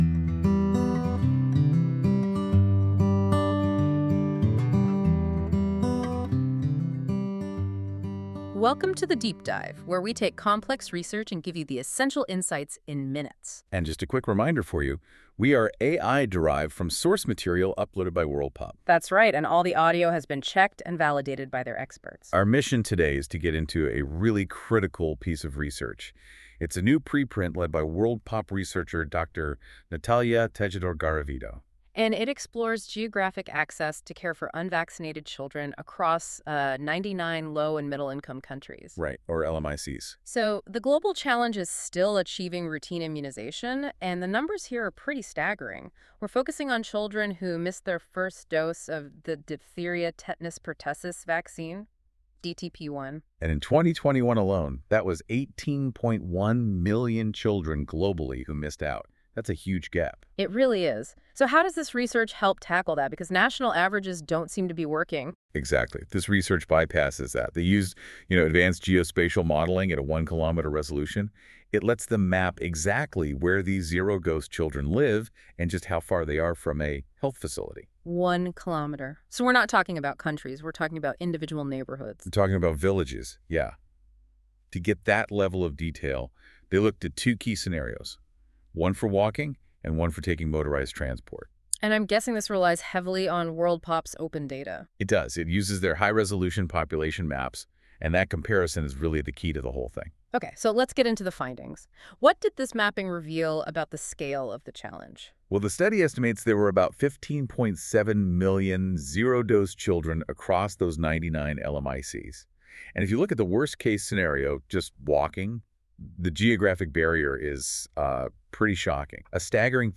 This feature uses AI to create a podcast-like audio conversation between two AI-derived hosts that summarise key points of documents - in this case the “Geographic access to care for unvaccinated children in LMICs” preprint linked below.
Music: My Guitar, Lowtone Music, Free Music Archive (CC BY-NC-ND)